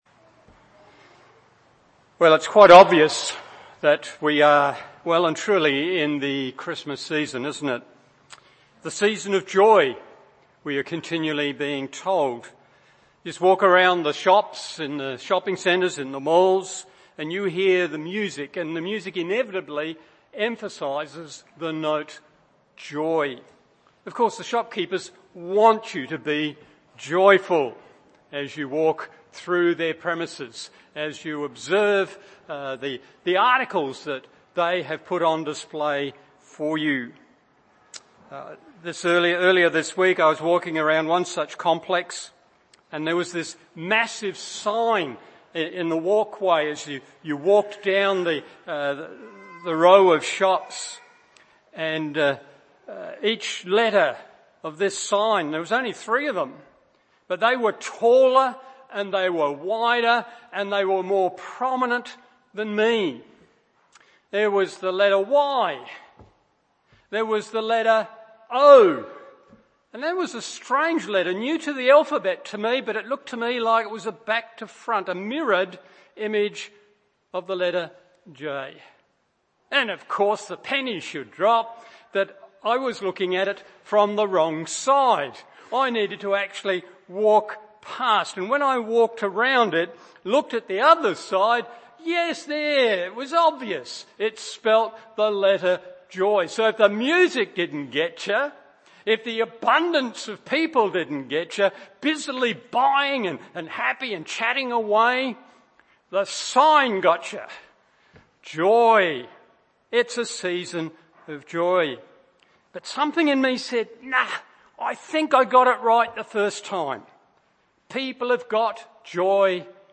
Morning Service Psalm 107 1. Consider the Lord 2. Communicate to the Lord 3.